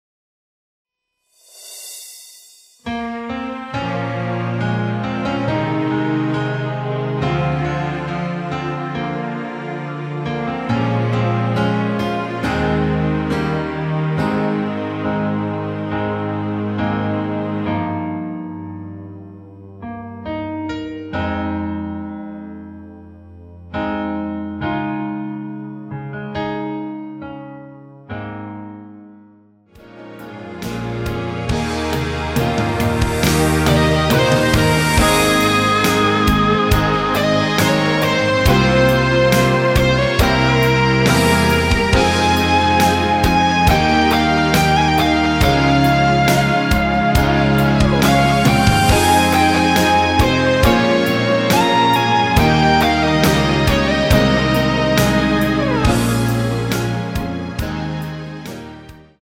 원곡의 보컬 목소리를 MR에 약하게 넣어서 제작한 MR이며
노래 부르 시는 분의 목소리가 크게 들리며 원곡의 목소리는 코러스 처럼 약하게 들리게 됩니다.